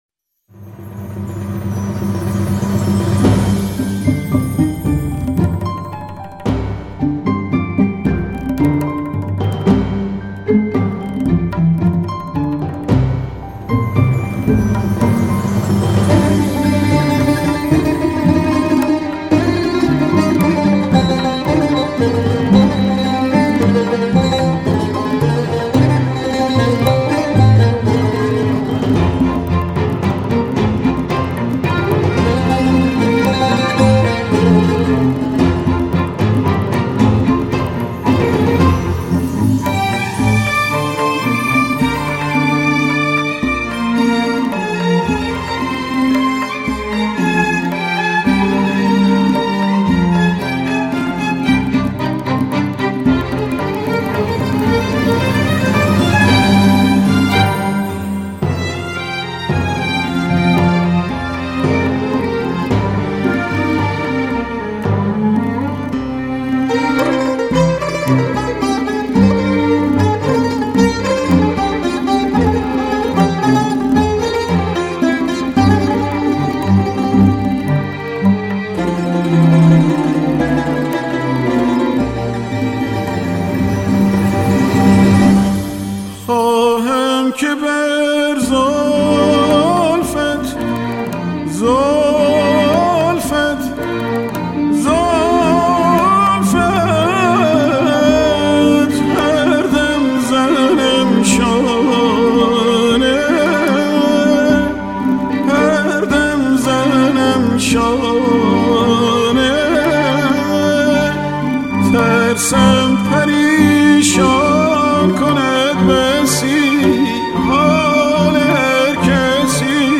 آهنگ عاشقانه قدیمی